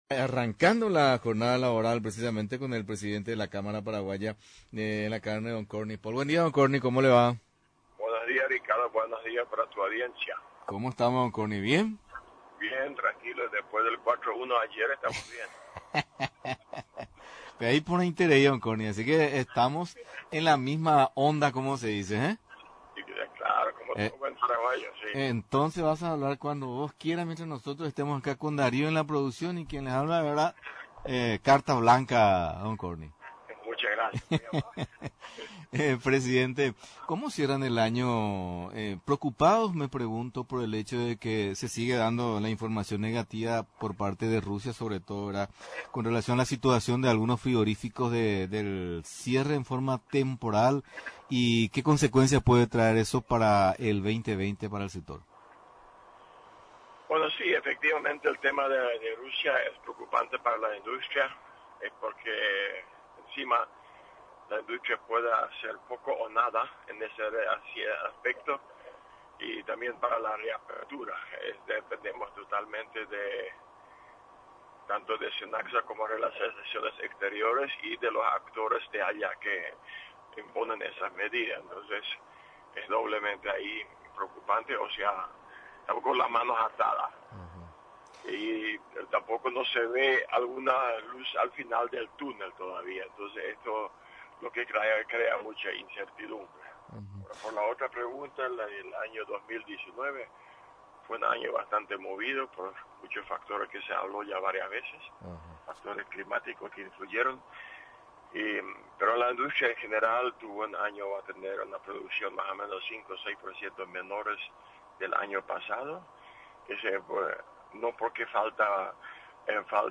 En entrevista con Paraguay Puede!!! 920 AM